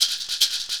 Shaker 10.wav